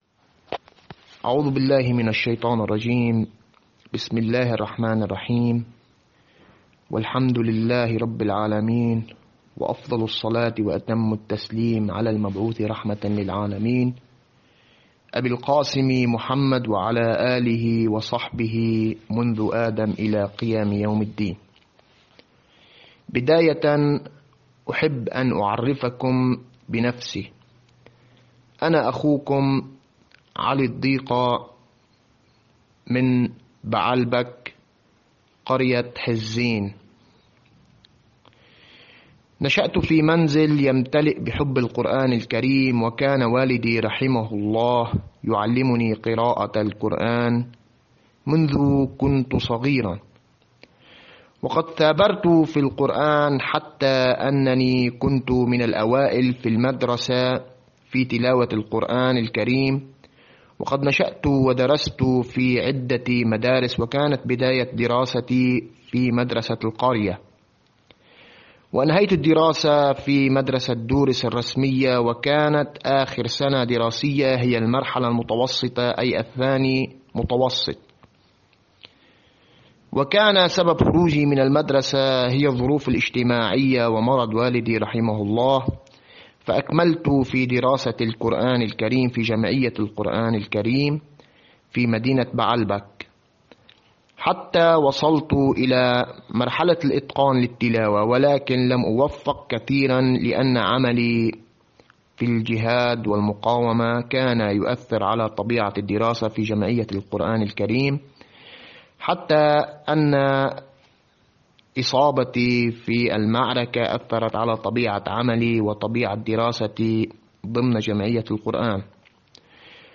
قارئ لبناني لـ"إکنا":